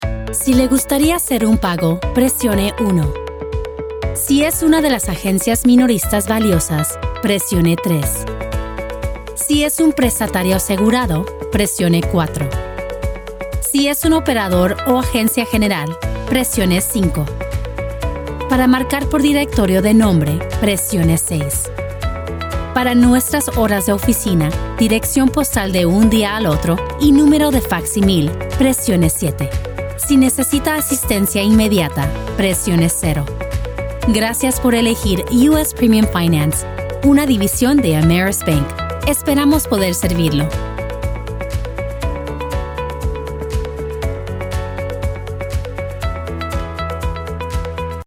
Female Voice Over, Dan Wachs Talent Agency.
Bilingual Voice Actor.  English, Neutral Spanish, Columbian and Mexican Dialects.
IVR - Spanish